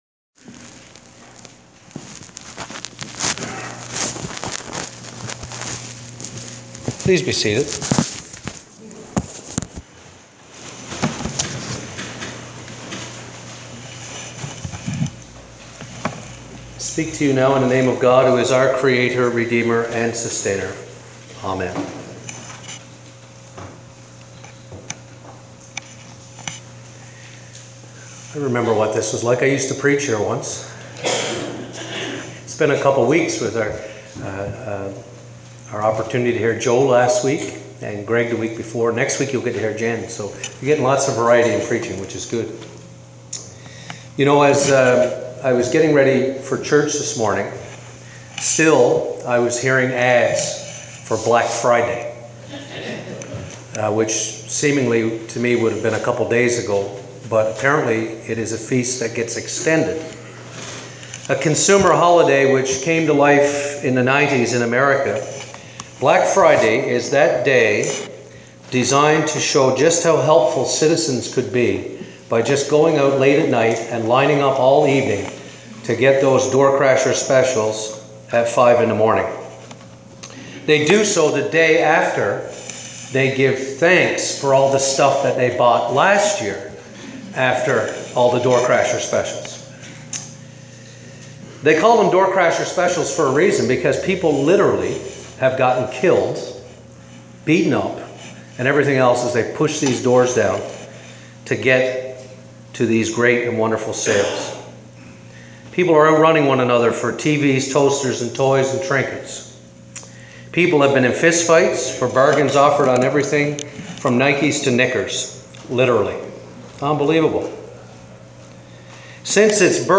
I am trying out something new — I will post here from time to time my Homilies in Audio and sometimes in print format. here is the Preaching for Advent 1.